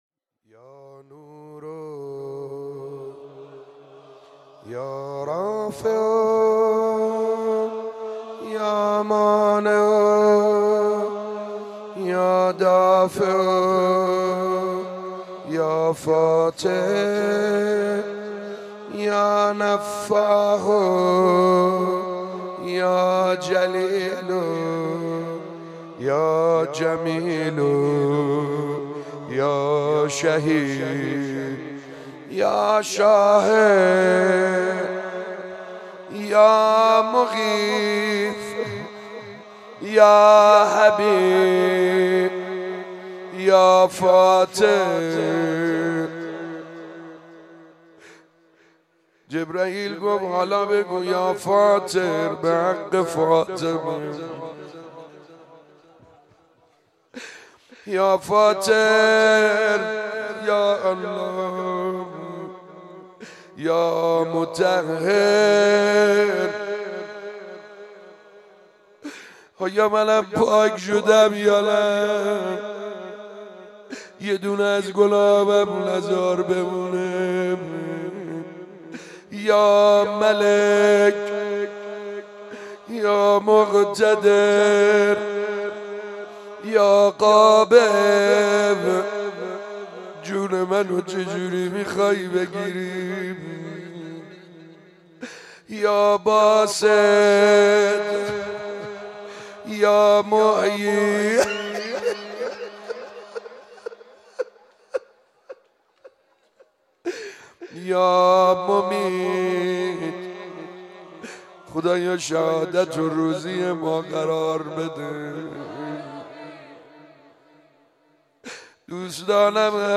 شب عید فطر95دعا خوانی_یا نور یا الله